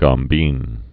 (gŏm-bēn)